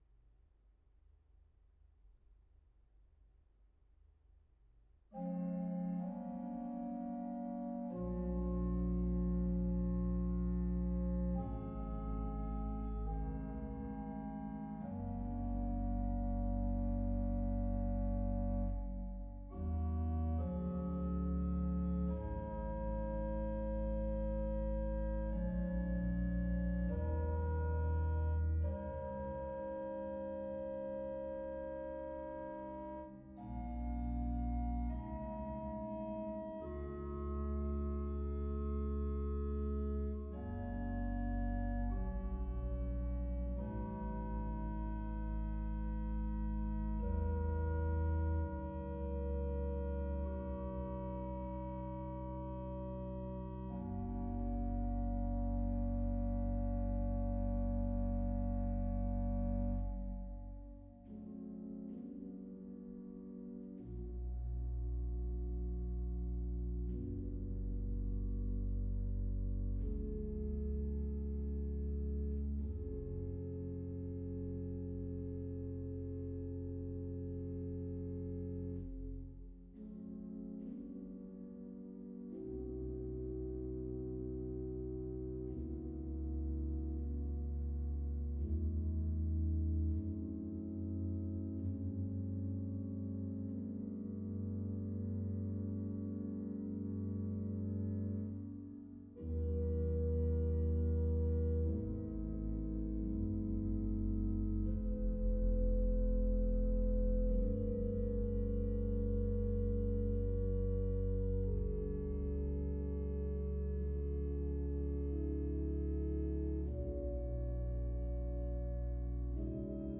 organ Download PDF Duration